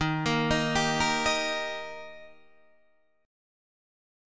D# chord